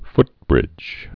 (ftbrĭj)